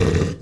spawners_mobs_uruk_hai_hit.4.ogg